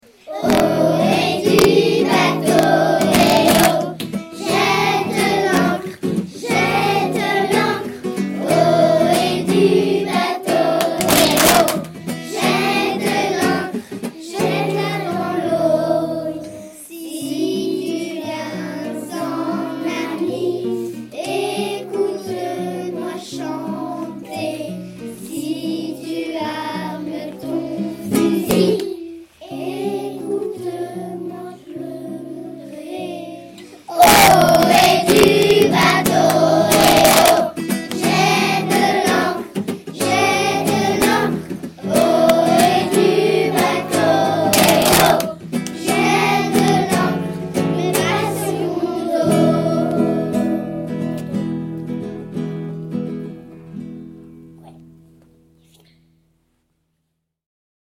Nos séances commencent toujours par un échauffement du corps, des voix…
gs_-_la_baleine_-_chanson.mp3